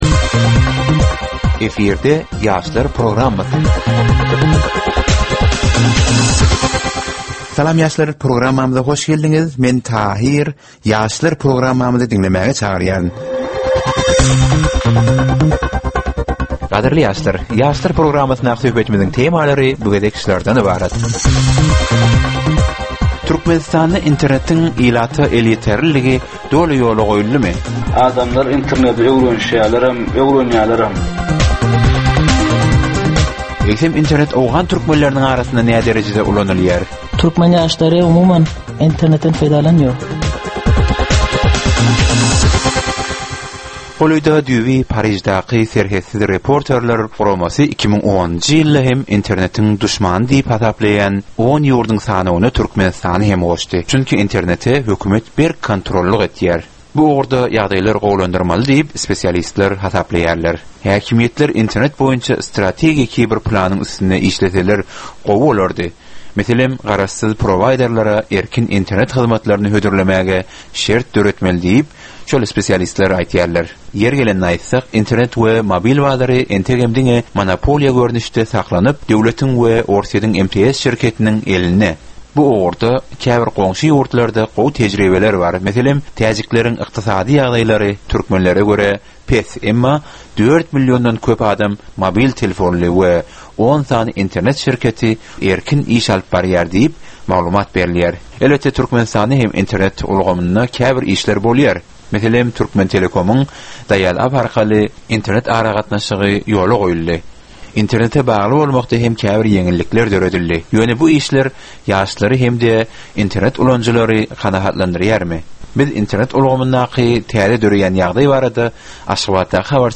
Gepleşigiň dowmynda aýdym-sazlar hem eşitdirilýär.